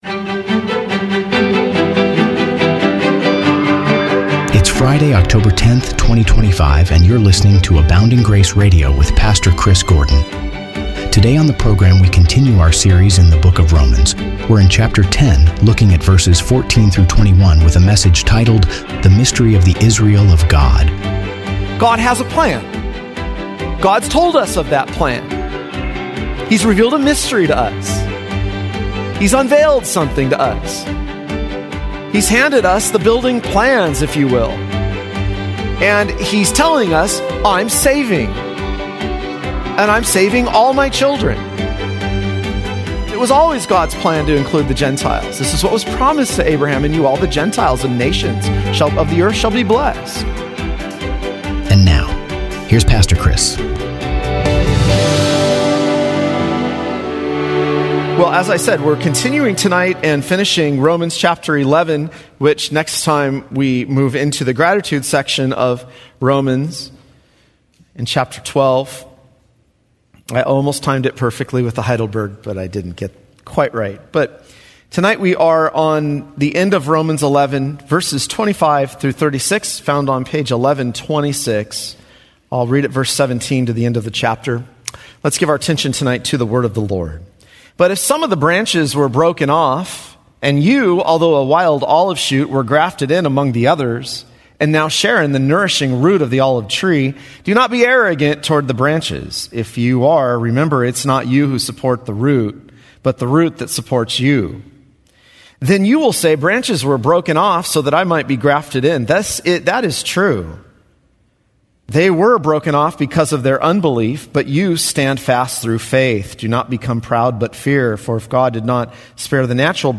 He emphasized that God is using Gentile inclusion to provoke Jewish jealousy and bring some back to faith, forming one unified people of God. The sermon warned against Gentile pride and arrogance toward unbelieving Jews, reminding listeners that their salvation came through God's grace, not merit.